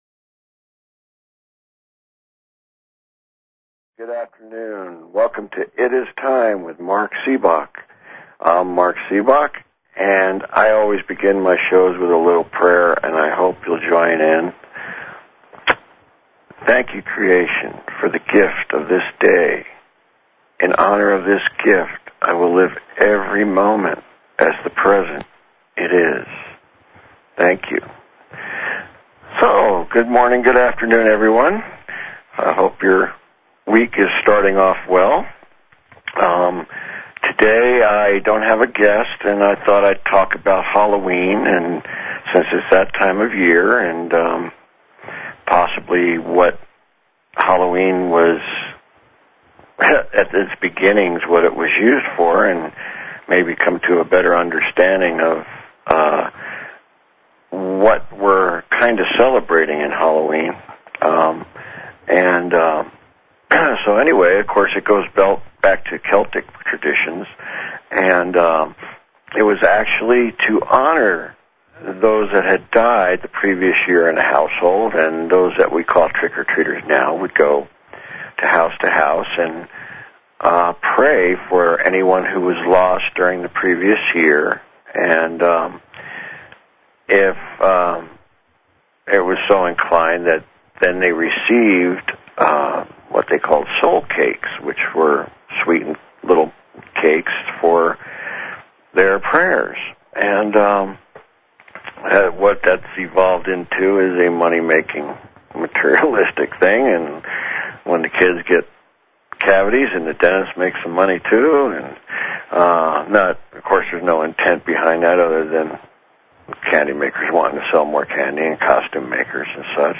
Talk Show Episode, Audio Podcast, It_IS_Time and Courtesy of BBS Radio on , show guests , about , categorized as